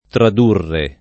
tradurre
tradurre [ trad 2 rre ] v.;